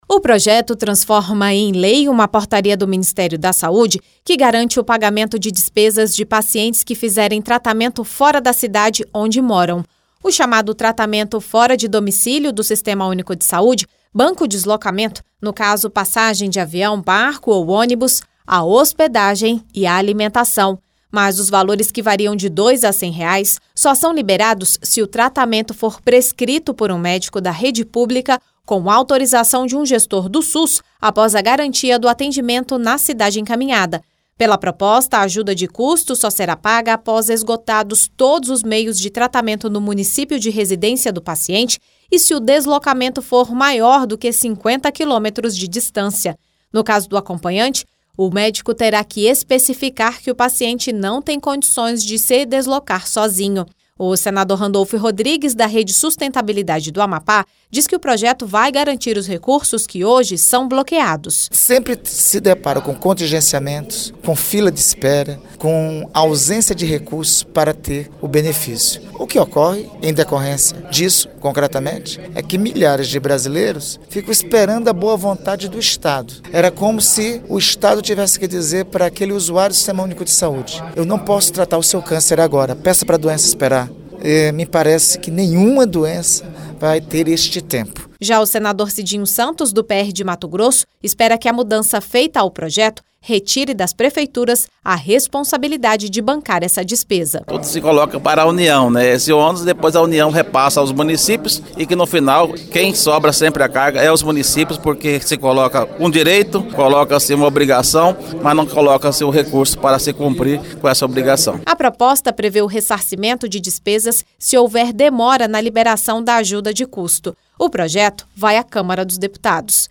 O senador Randolfe Rodrigues (Rede-AP) afirmou que o projeto garantirá os recursos hoje bloqueados.